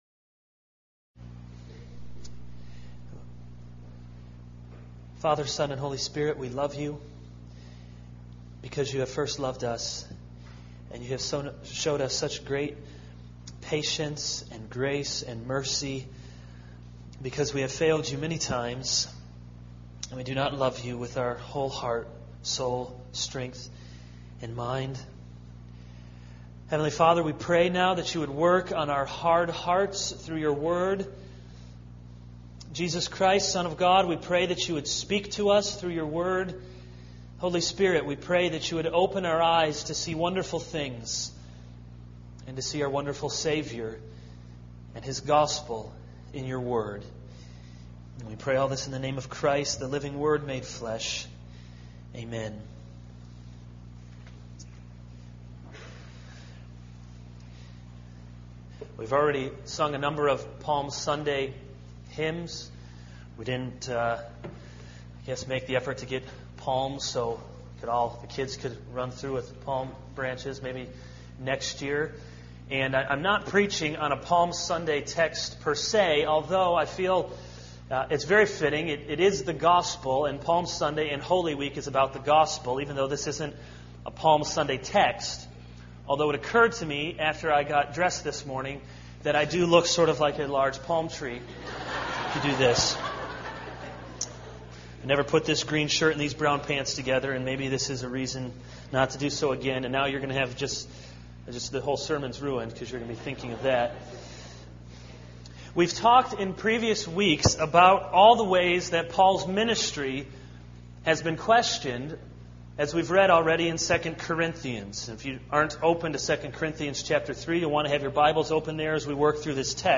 This is a sermon on 2 Corinthians 3:7-11.